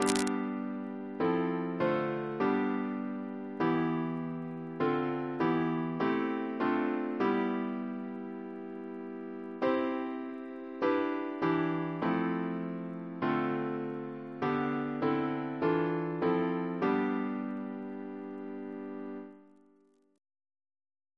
Double chant in G Composer: David Hurd (b.1950) Reference psalters: ACP: 240